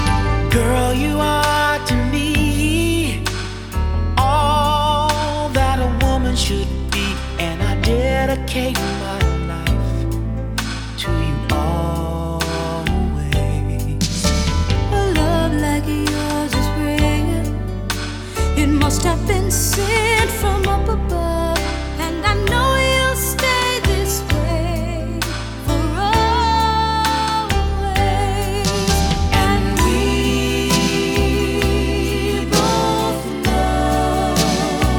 Жанр: Поп / R&b / Рок / Соул